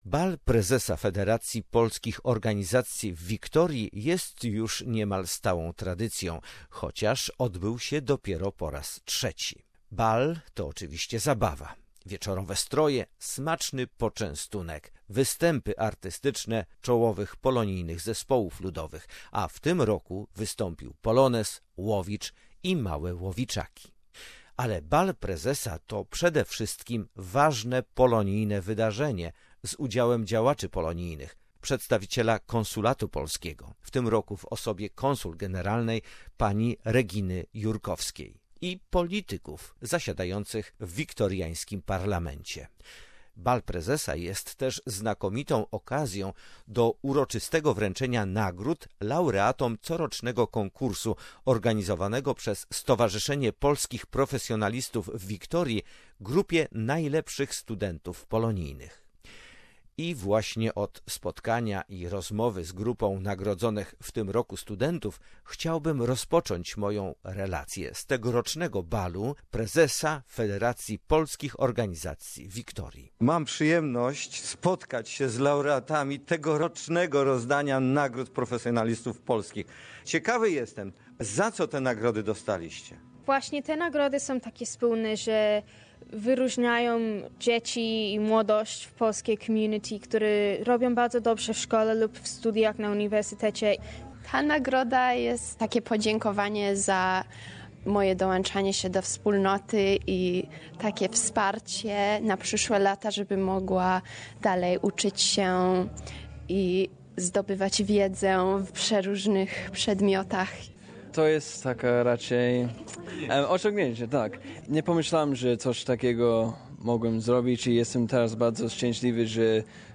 Gala Ball